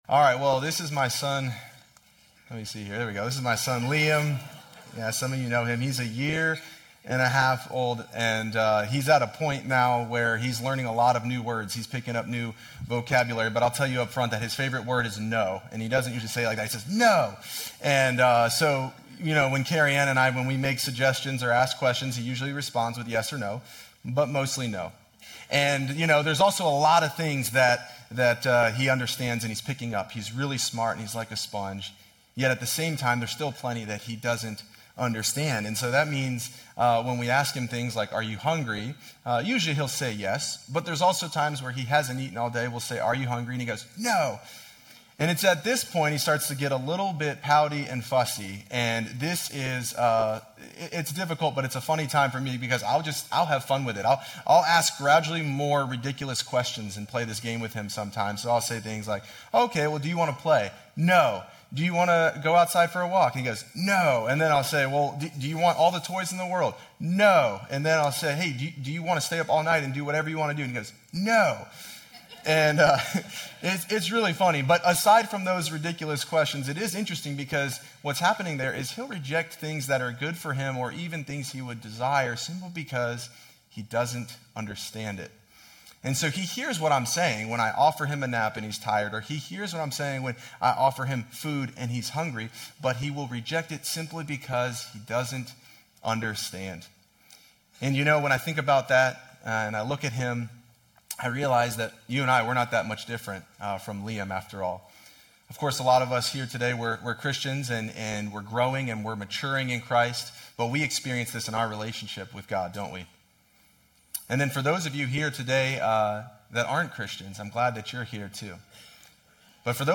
This sermon is a great reminder of how we can live out this Biblical teaching every day.